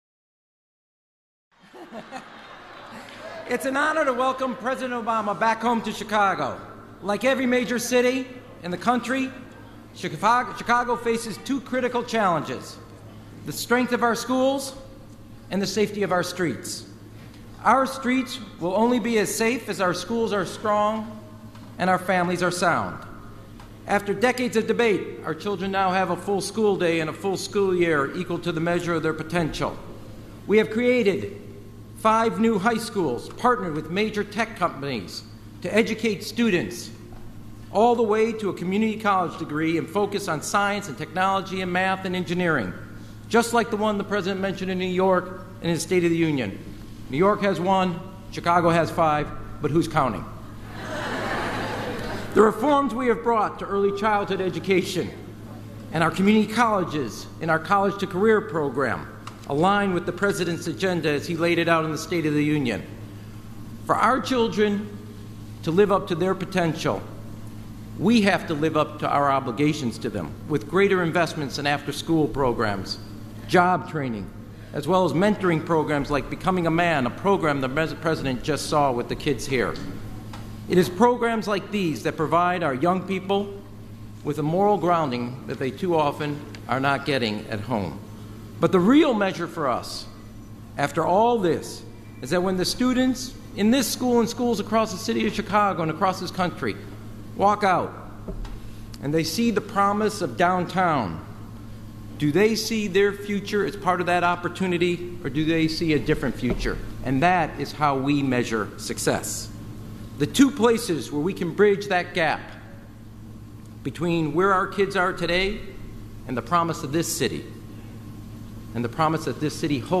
U.S. President Barack Obama speaks at Hyde Park Academy